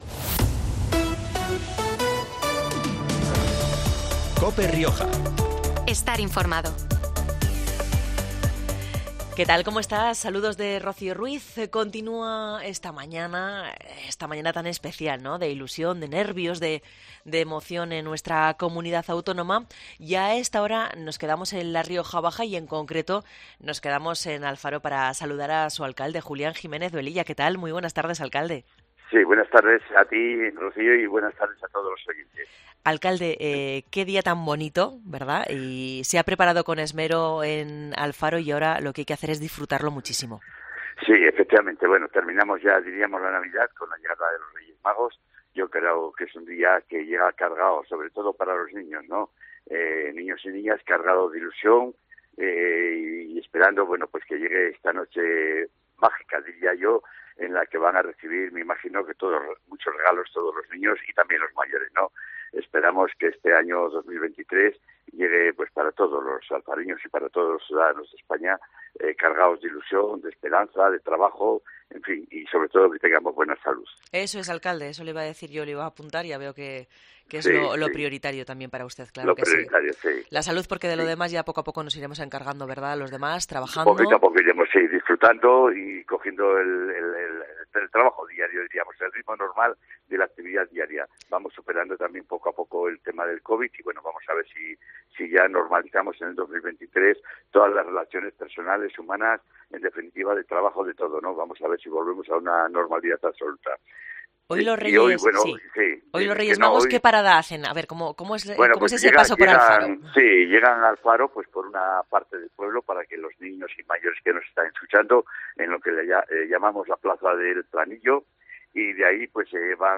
Arrancará a las 19:00 horas de esta tarde. ctv-vgm-alfafro-cartel Julián Jiménez Velilla, alcalde de Alfaro, ha pasado por los micrófonos de COPE Rioja para contarnos todos los detalles de esta mágica visita. Escucha aquí la entrevista completa.